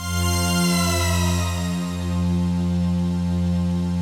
ATMOPAD34 -LR.wav